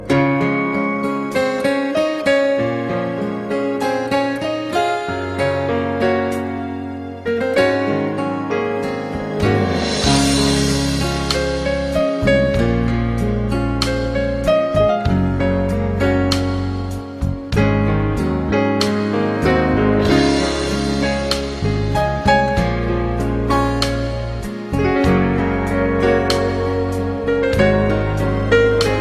Valentine Tone